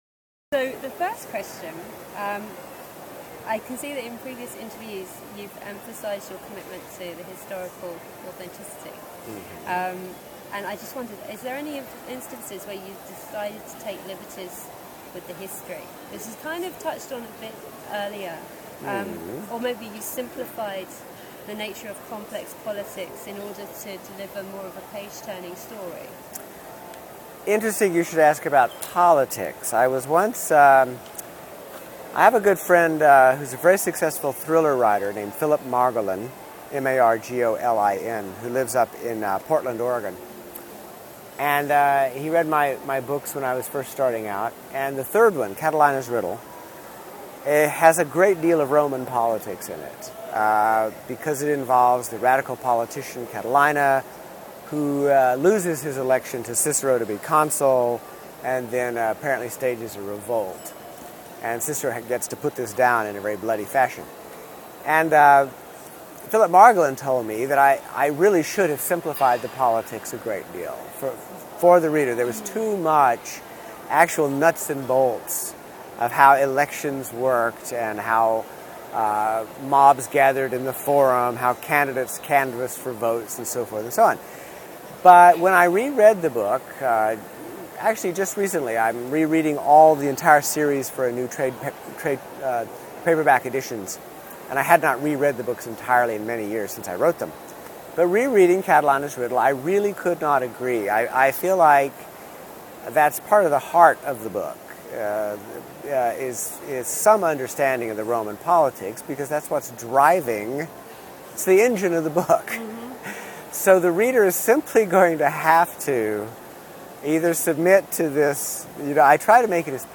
File | Interview | ID: b5644s11t | University of Hull Digital Collections